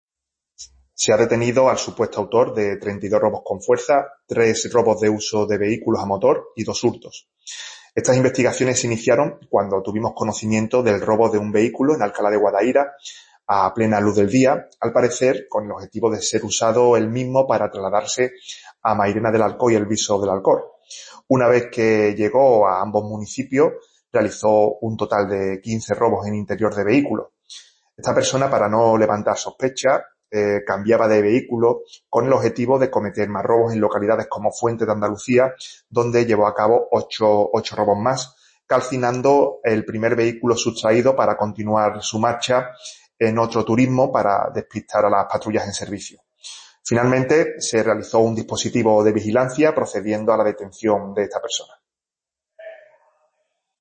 Audio de Portavoz Policial Explicando la Operación: